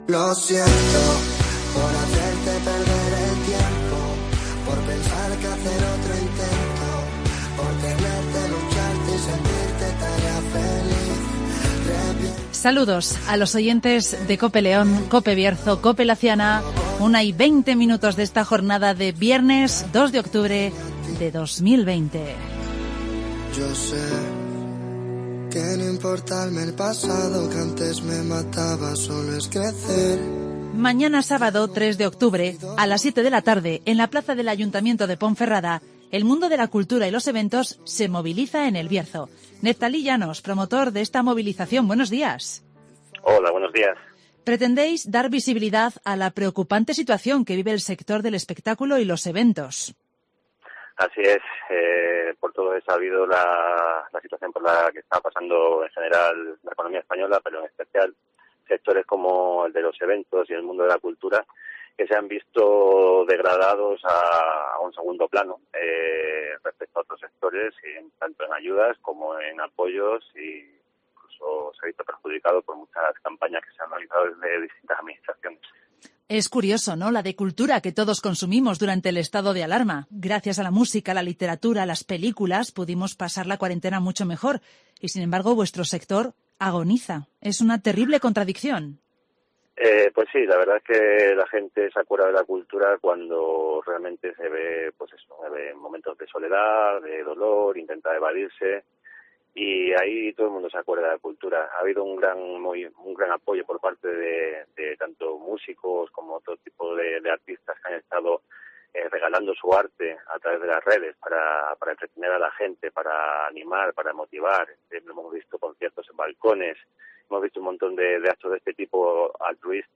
El mundo de la cultura y los eventos se moviliza en el Bierzo (Entrevista